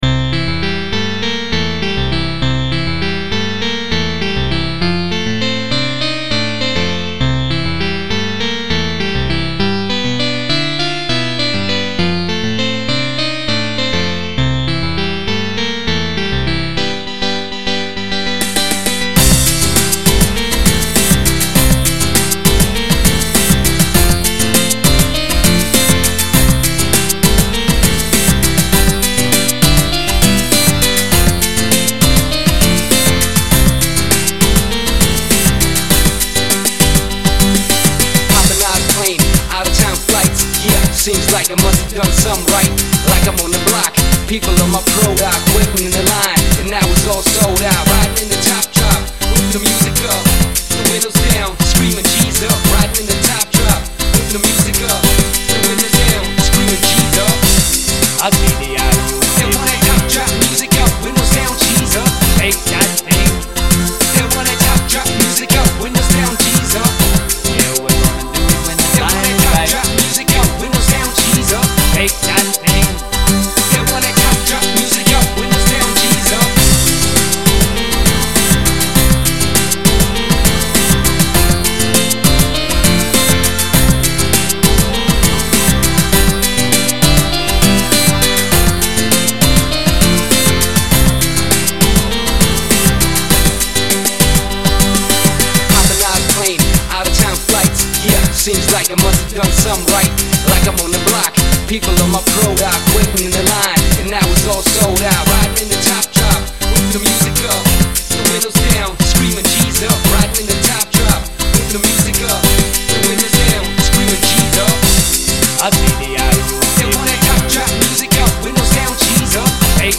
Что звучание у пиано ужастное - знаю, но играл на еже сам! (Любитель как-то очень давно указывал) Классика Рок-н-ролла! Так называемый квадрат!
Сэмплы: Свои, вокал - ежовский сбор